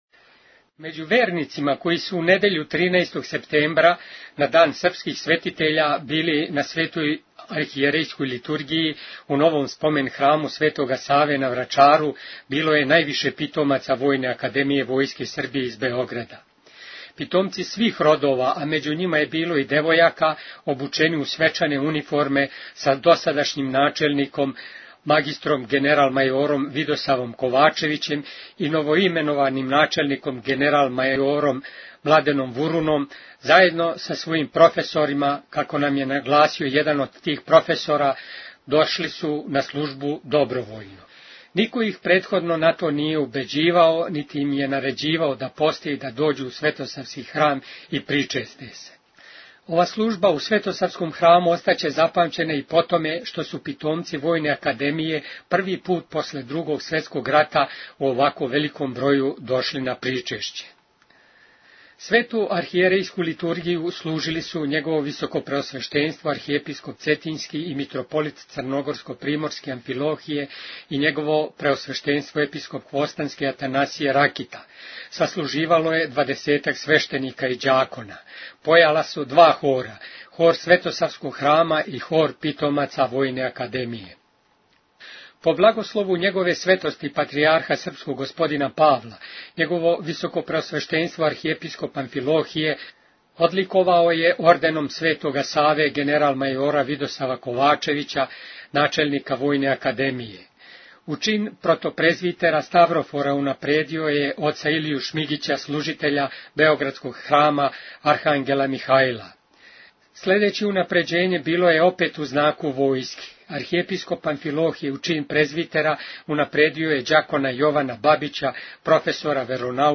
Архиепископ Г. Амфилохије и Епископ Г. Атанасије (Ракита) служили у Спомен храму на Врачару, 13. септембар 2009 | Радио Светигора
Sveta Arhijerejska Liturgija na Vracaru
Свету Архијерејску Литургију служили су Његово Високопреосвештенство Архиепископ цетињски и Митрополит црногорско – приморски Г. Амфилохије и Његово Преосвештенство Епископ хвостански Г. Атанасије (Ракита).
И бесједа Архиепископа Амфилохија била је упућена питомцима Војне академије и њиховим професорима.